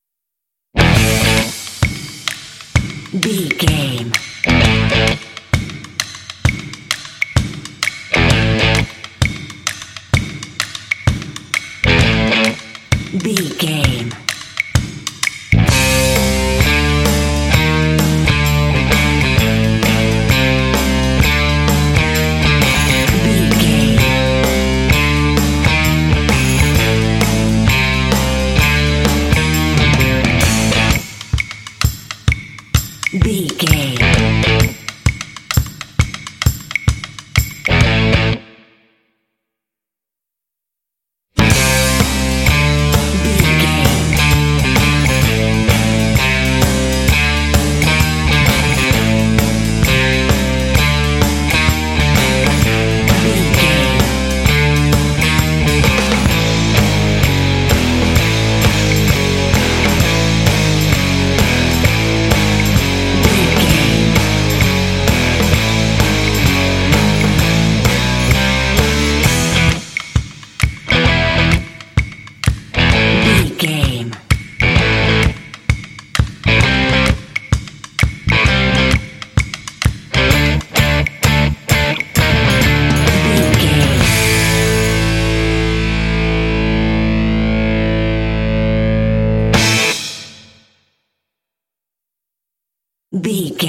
This groovy track is ideal for action and sports games.
Aeolian/Minor
groovy
electric guitar
percussion
drums
bass guitar
rock
classic rock